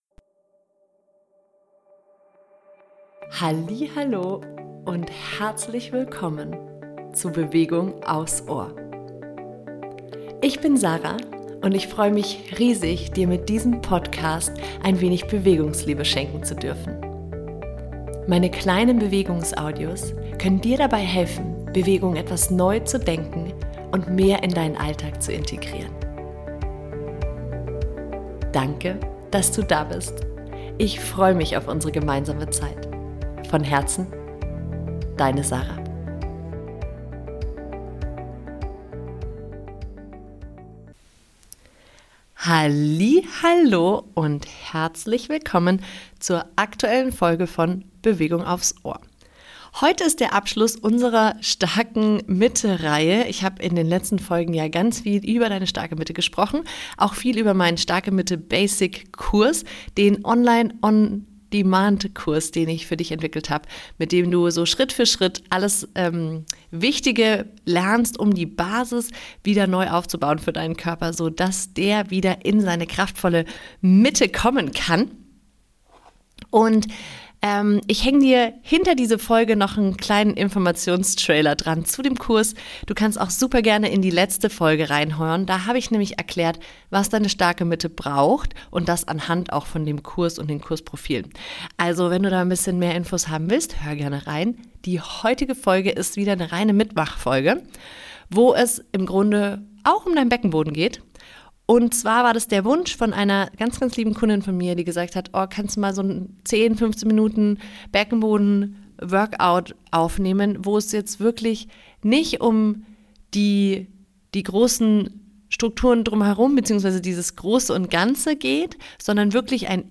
In dieser Folge leite ich ein paar klassische Beckenboden Übungen an. Das kleine Workout eignet sich perfekt zum regelmäßigen Üben.